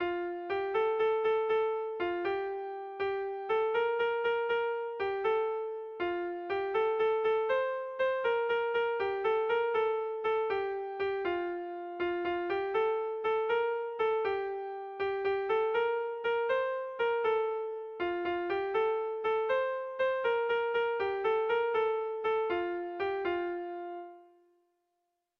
Air de bertsos - Voir fiche   Pour savoir plus sur cette section
Gabonetakoa
Zortzikoa, berdinaren moldekoa, 6 puntuz (hg) / Sei puntukoa, berdinaren moldekoa (ip)
ABD....